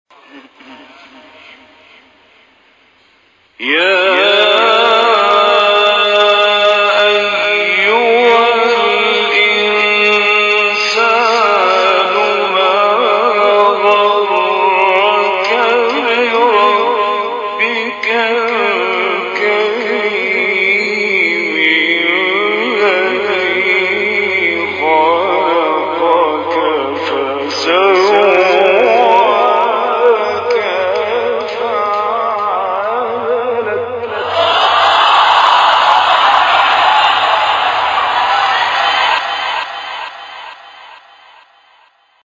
گروه شبکه اجتماعی: مقاطع صوتی از تلاوت‌های قاریان برجسته مصری را می‌شنوید.